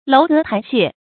樓閣臺榭 注音： ㄌㄡˊ ㄍㄜˊ ㄊㄞˊ ㄒㄧㄝ ˋ 讀音讀法： 意思解釋： 樓：高樓；閣：架空的樓；臺：土筑的高壇；榭：臺上的房屋。